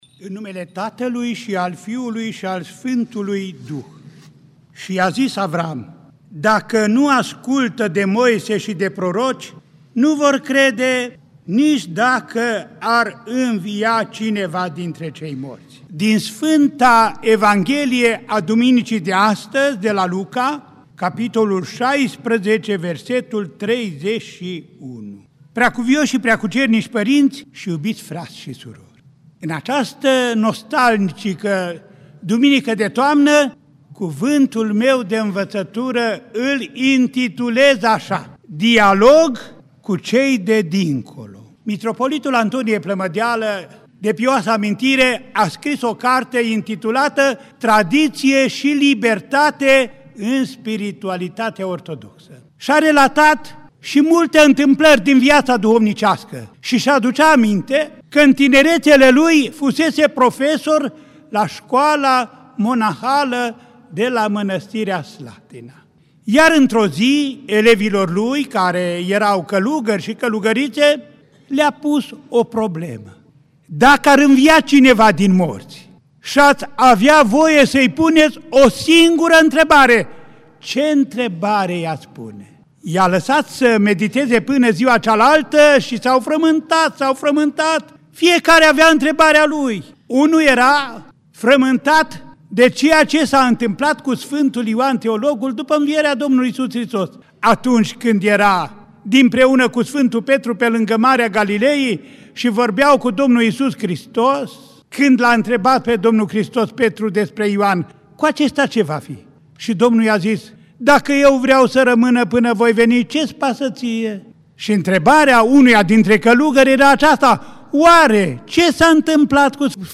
Cuvânt de învățătură al Înaltpreasfințitul Părinte Andrei, Mitropolitul Clujului în Catedrala Mitropolitană din Cluj-Napoca. 5 noiembrie 2017
nov. 5, 2017 | Predici IPS Andrei